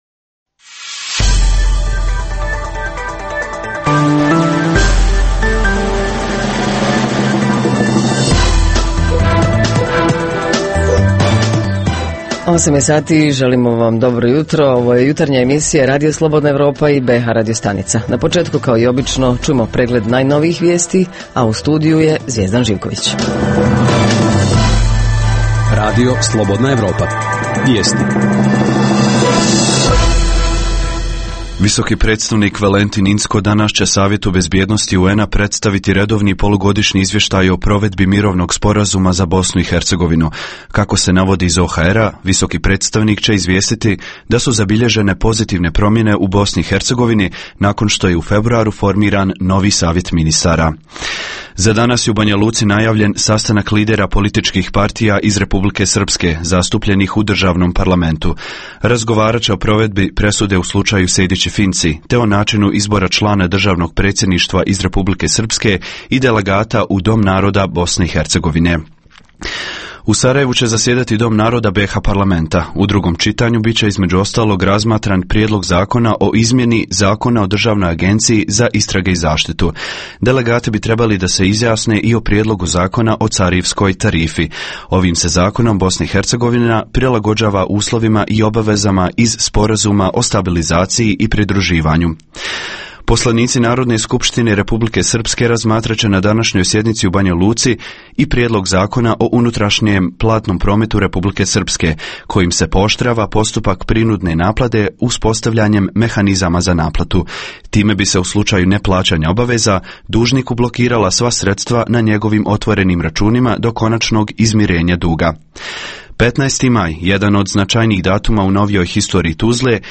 Jutarnji program za BiH koji se emituje uživo.
Redovna rubrika Radija 27 utorkom je “Svijet interneta". Redovni sadržaji jutarnjeg programa za BiH su i vijesti i muzika.